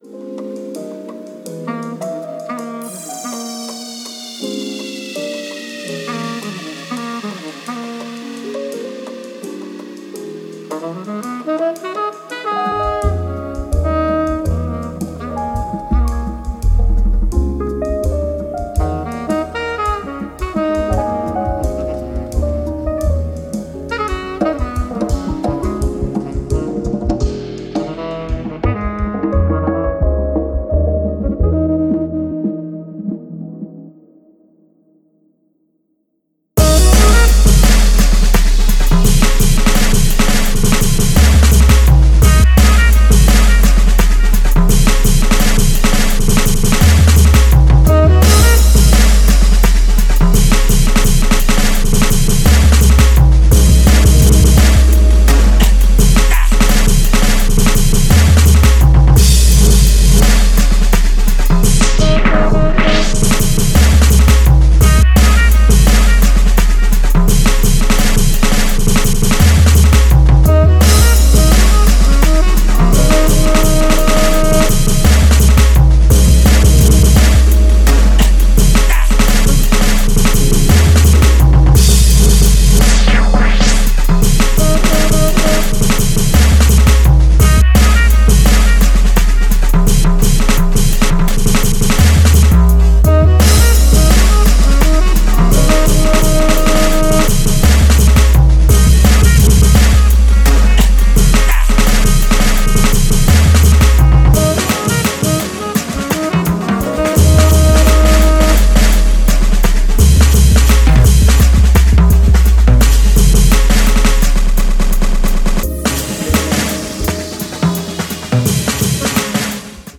TOP >Vinyl >Drum & Bass / Jungle